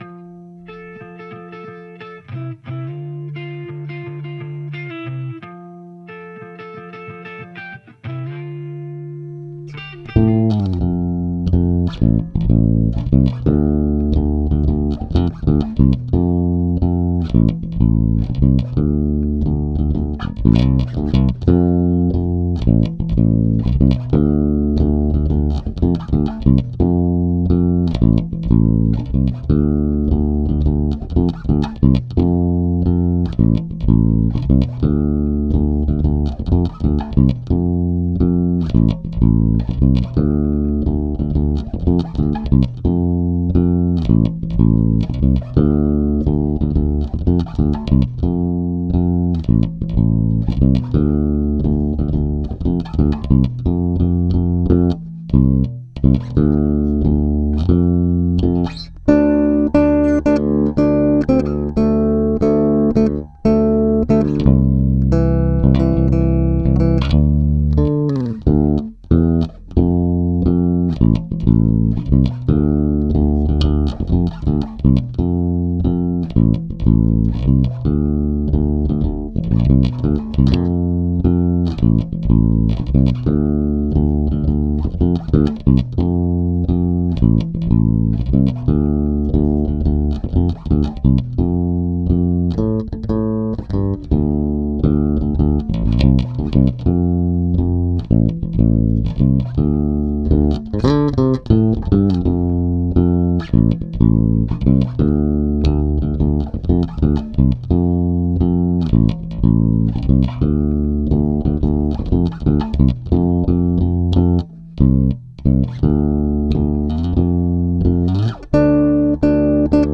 (bass only)